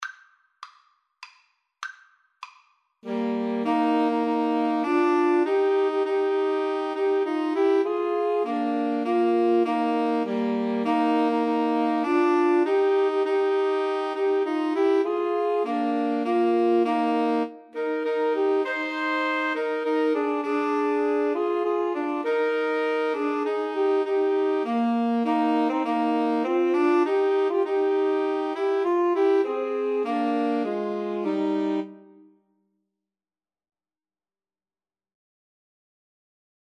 Alto SaxophoneTenor SaxophoneBaritone Saxophone
3/4 (View more 3/4 Music)
Eb major (Sounding Pitch) (View more Eb major Music for Woodwind Trio )
Woodwind Trio  (View more Easy Woodwind Trio Music)
Traditional (View more Traditional Woodwind Trio Music)